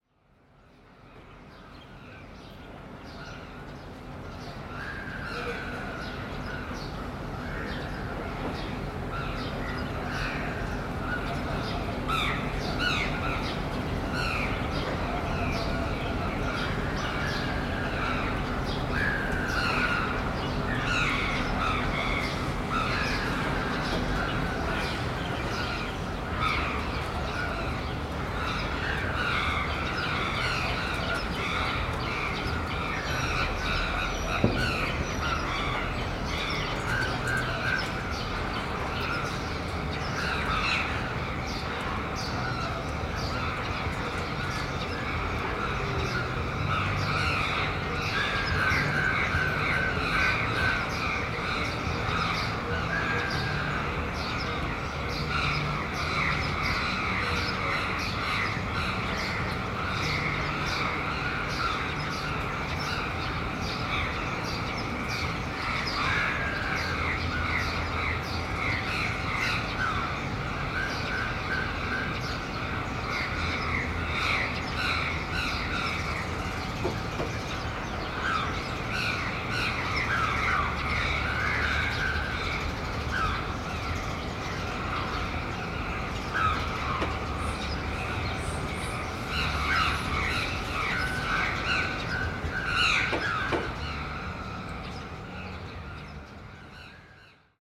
Morning crows, Yangon